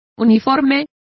Complete with pronunciation of the translation of equable.